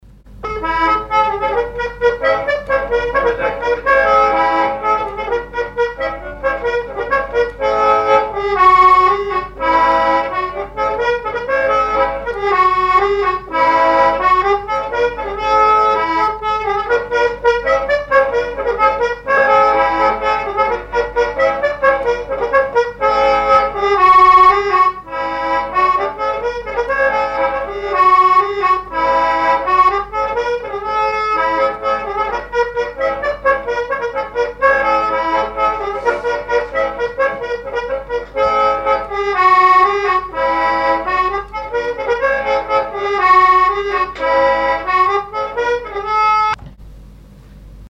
Sallertaine
danse : branle : courante, maraîchine
accordéoniste
Pièce musicale inédite